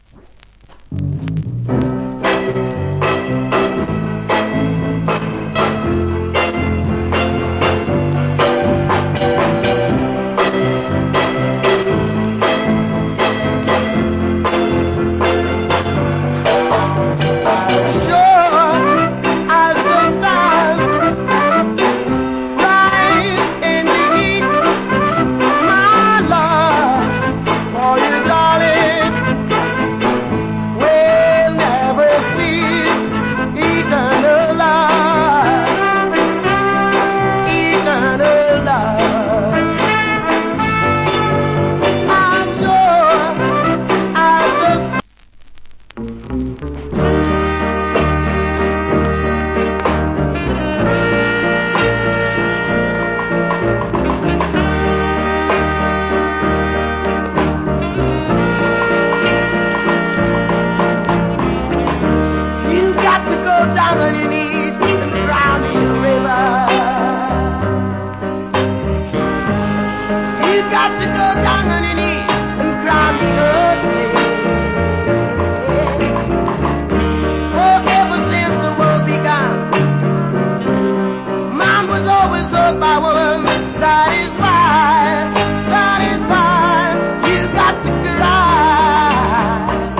Great Riddim. Rock Steady Inst.